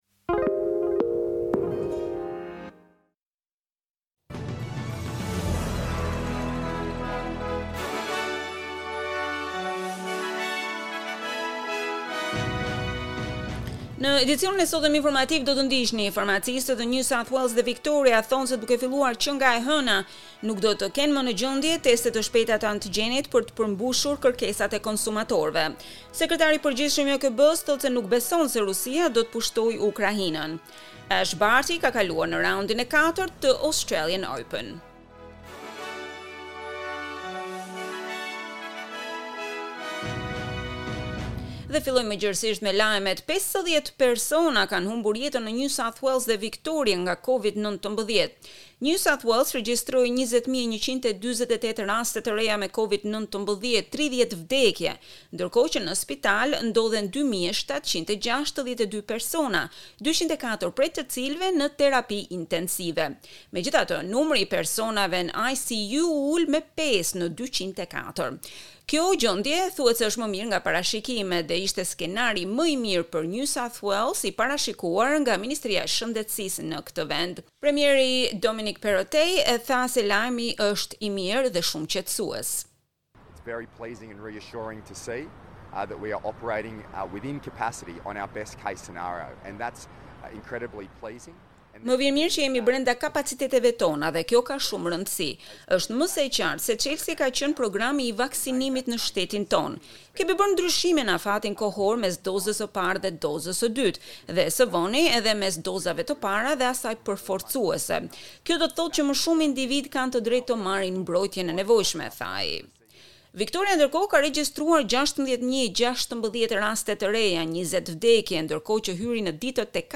SBS News Bulletin - 22 January 2022